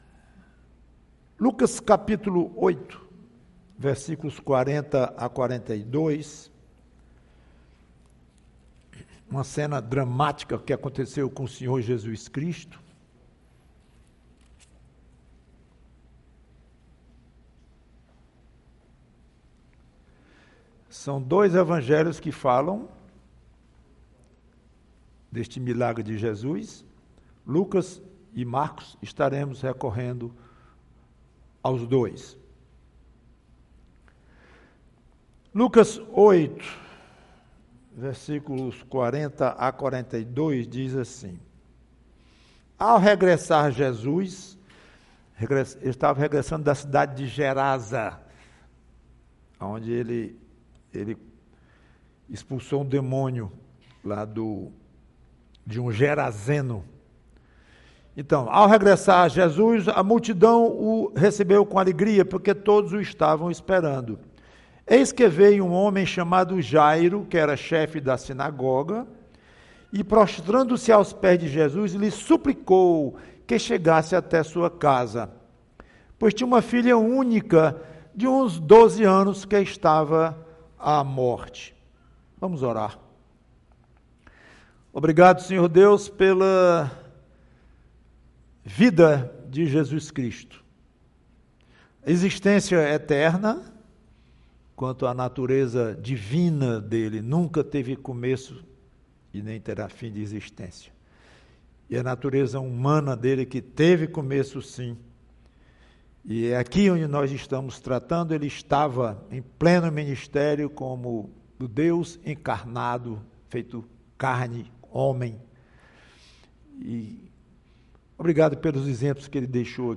PREGAÇÃO Nunca caçoe de Jesus!